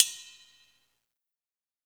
Closed Hats
HIHAT_ON_A_ROLL.wav